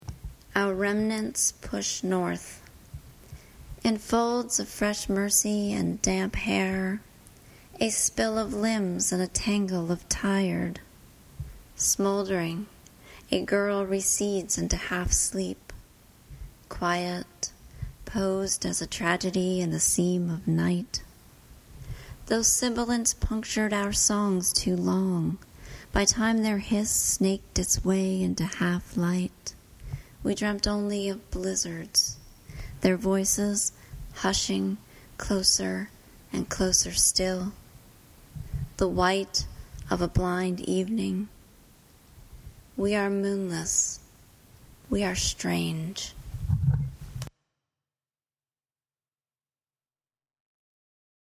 reads her poem,